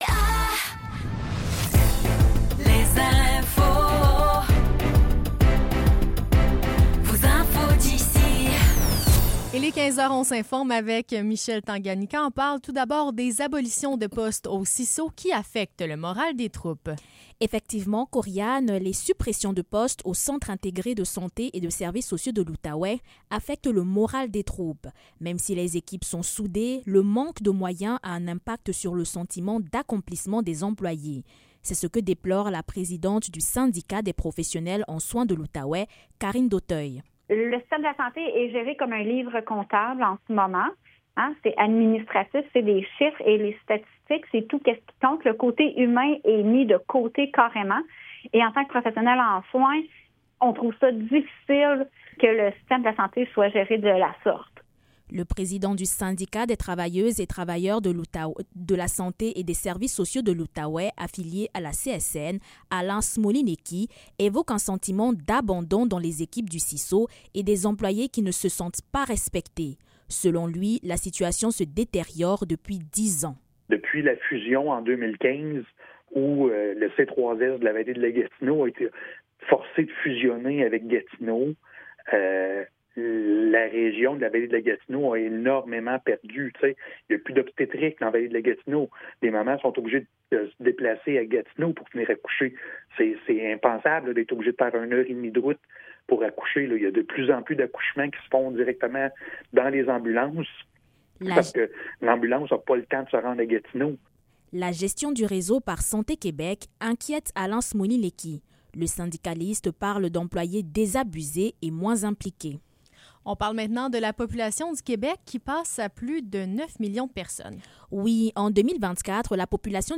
Nouvelles locales - 9 mai 2025 - 15 h